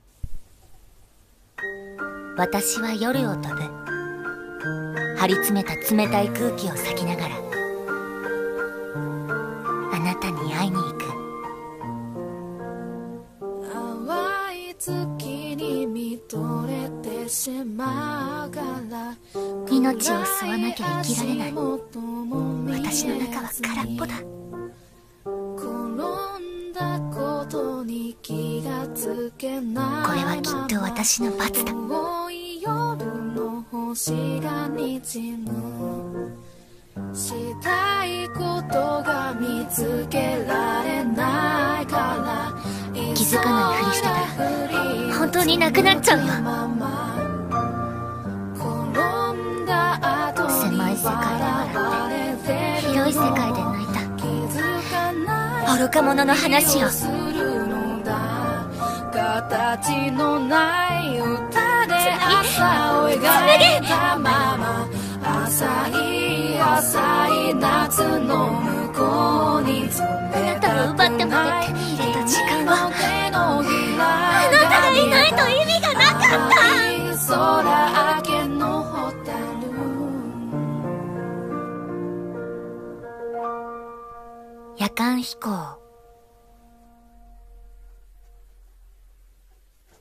【アニメ告知CM風声劇台本】夜間飛行【２人声劇】